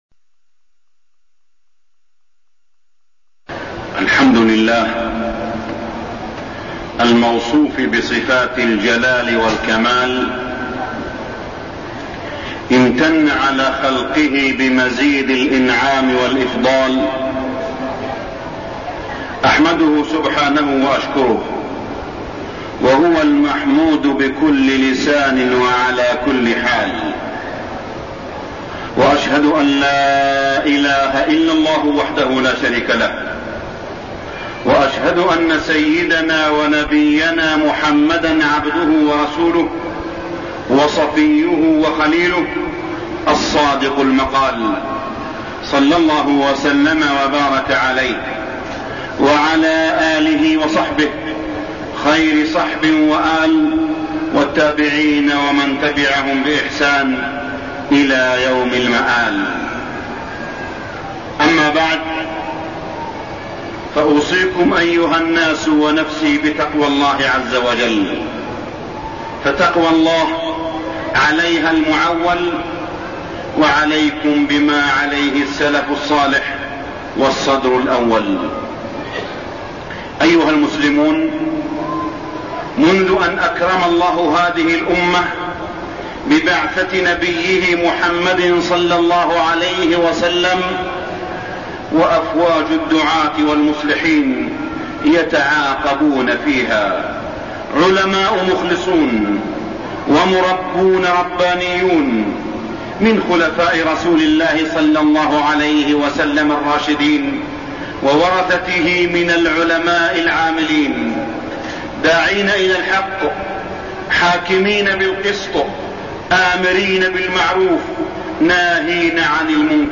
تاريخ النشر ١٢ شعبان ١٤١٥ هـ المكان: المسجد الحرام الشيخ: معالي الشيخ أ.د. صالح بن عبدالله بن حميد معالي الشيخ أ.د. صالح بن عبدالله بن حميد دعوة الشيخ محمد بن عبدالوهاب